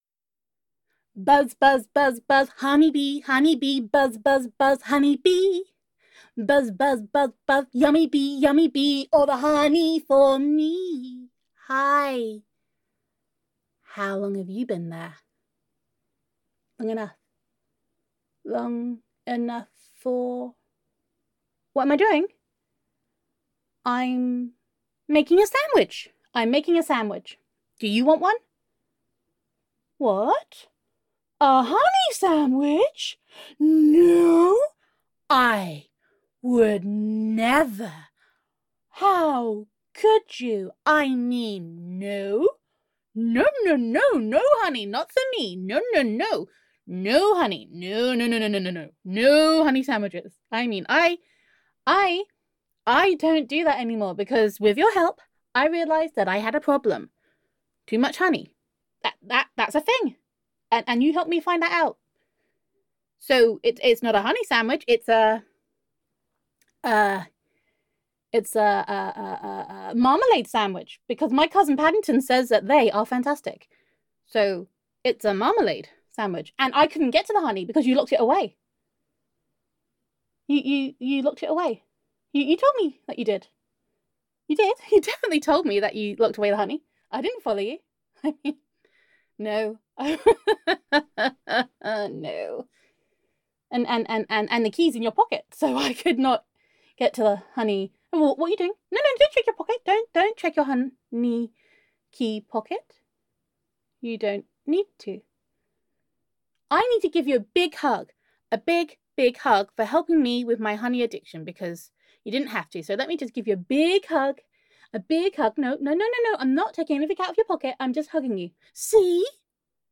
Peaky and unsaveable, but to cute to delete.
[F4A] Honey Bear [the Honey Song Always Gives Me Away][Learning How to Pickpocket From YouTube][Denial][Tea and Biscuits][Gender Neutral][Bear Girl Addicted to Honey]